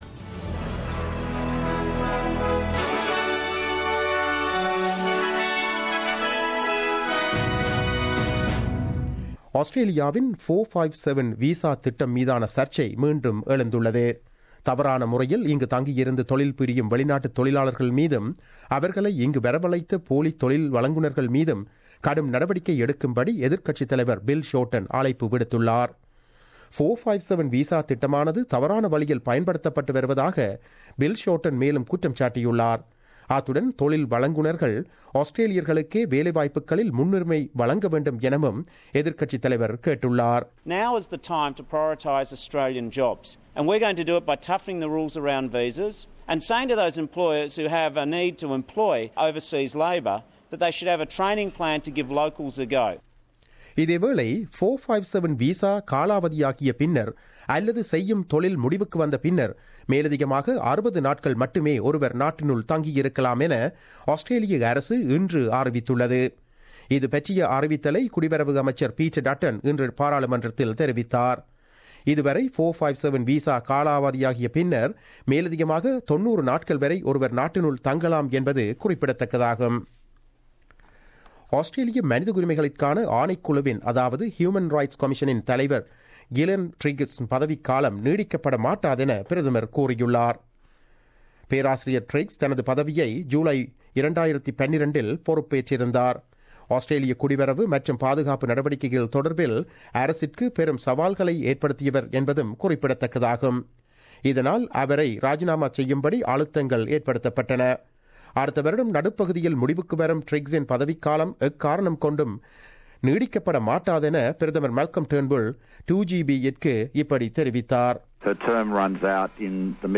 The news bulletin aired on 16 November 2016 at 8pm.